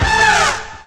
68_01_stabhit-A.wav